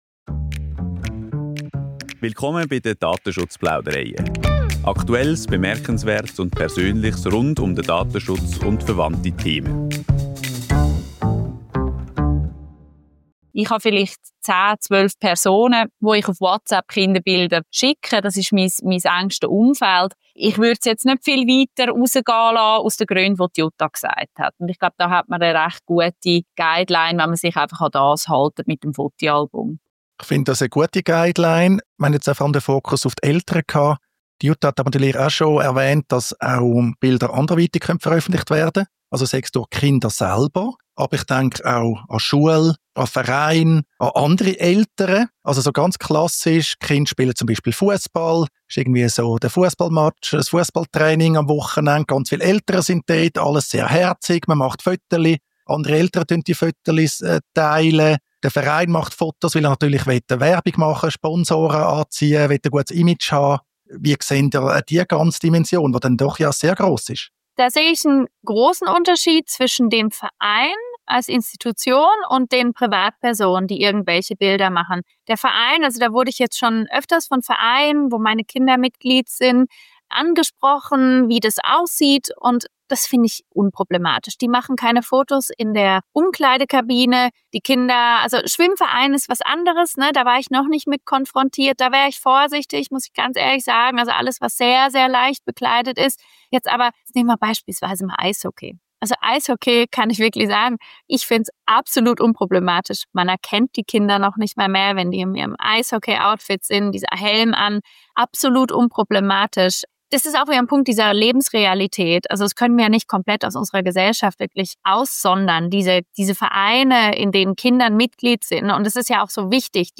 Die Spezialgäste